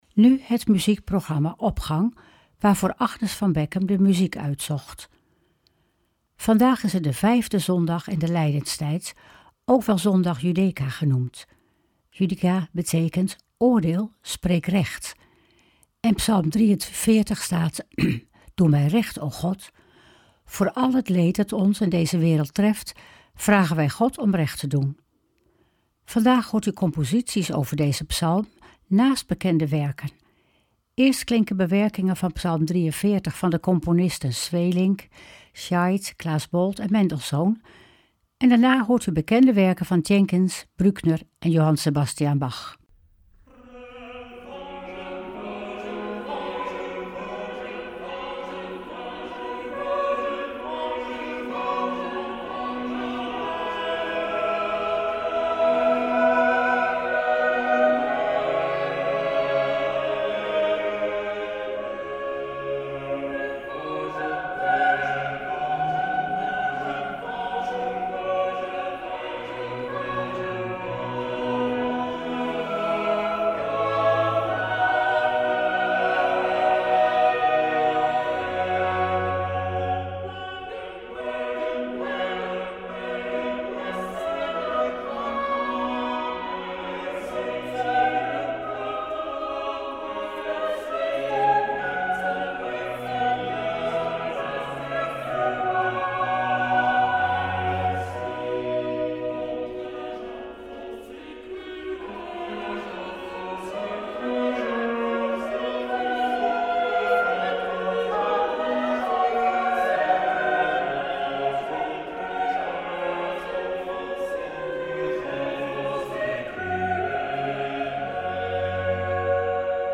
Opening van deze zondag met muziek, rechtstreeks vanuit onze studio.